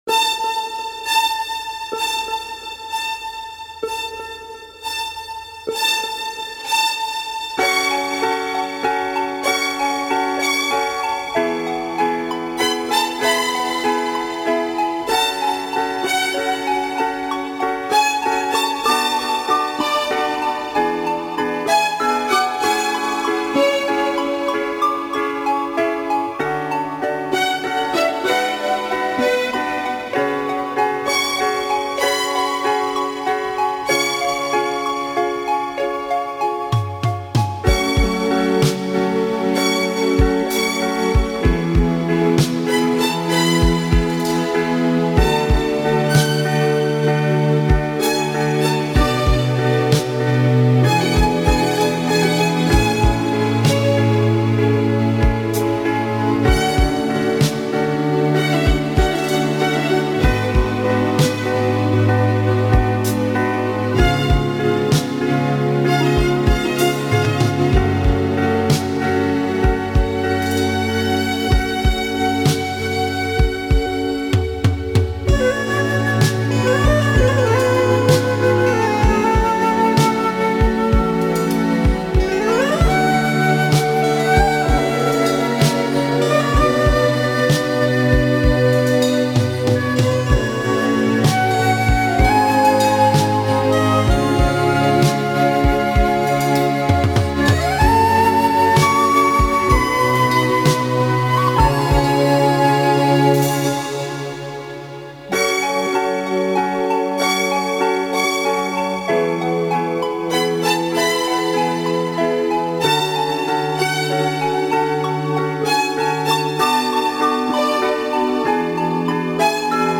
Genre: Sex Music.